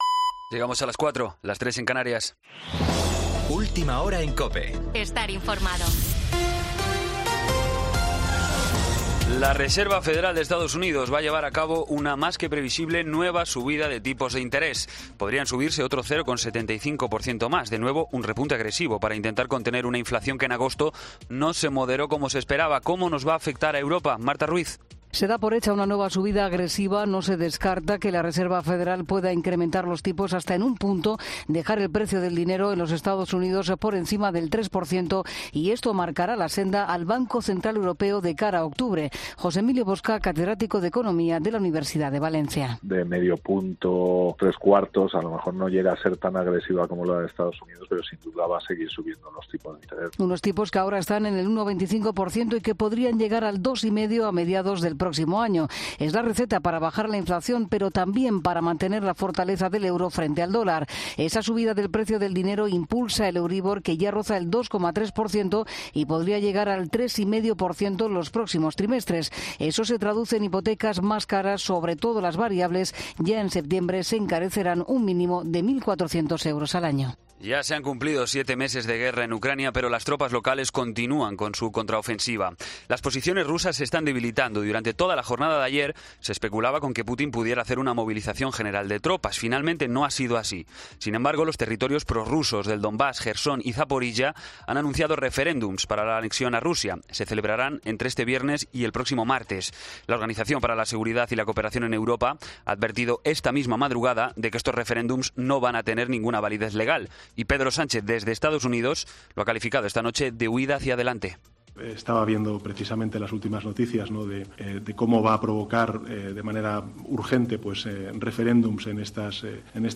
Boletín de noticias COPE del 21 de septiembre a las 04:00 hora
AUDIO: Actualización de noticias Herrera en COPE